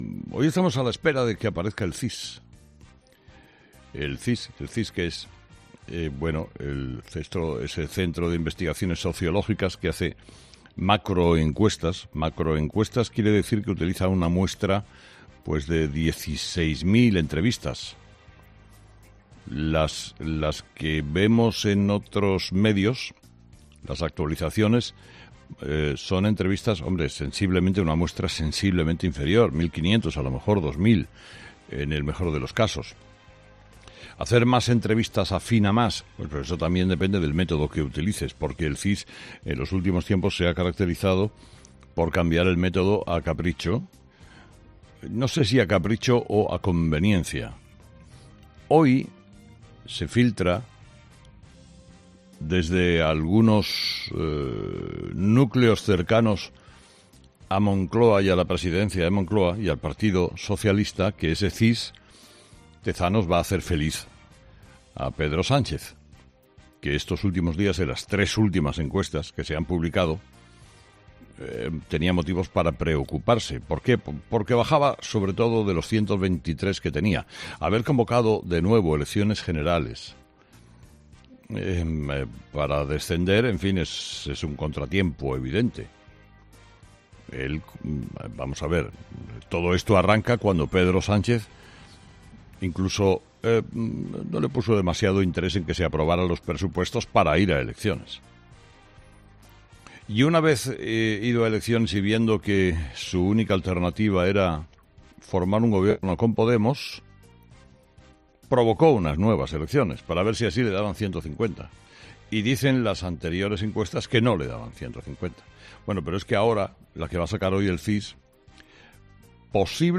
Carlos Herrera ha dedicado parte de su monólogo de las 06.00 a analizar los resultados del CIS que se esperan para el día de hoy